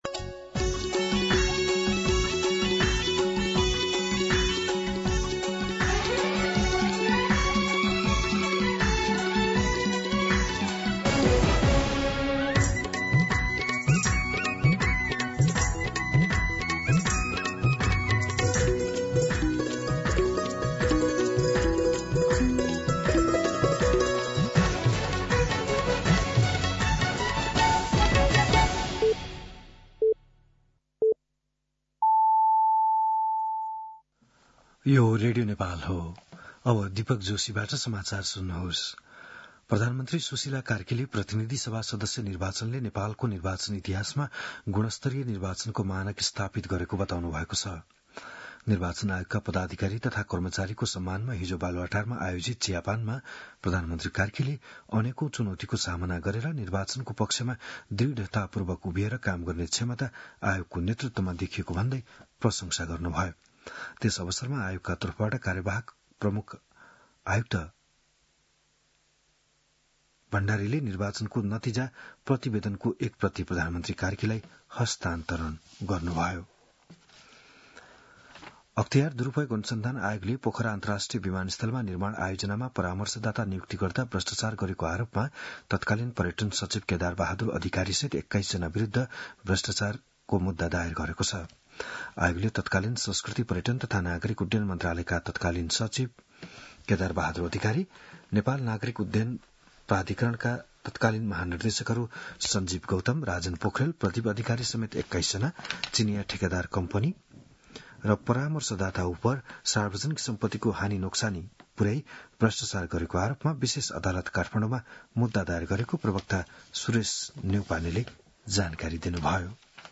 बिहान ११ बजेको नेपाली समाचार : ९ चैत , २०८२